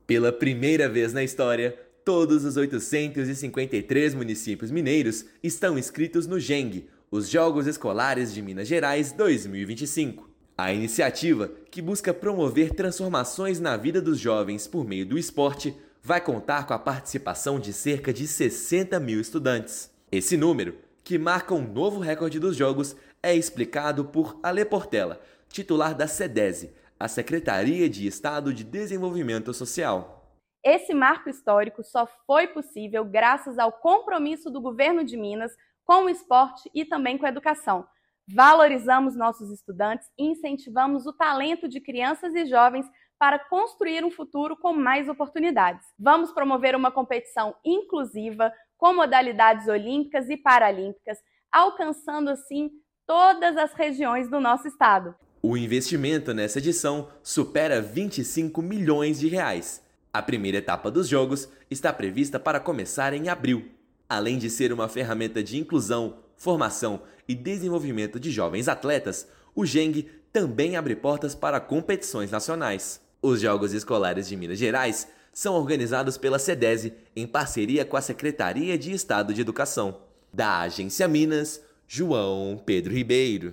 Jemg 2025 atinge adesão recorde e deve ter a participação de 60 mil estudantes, reforçando compromisso do Estado com a formação de novos talentos para o esporte. Ouça matéria de rádio.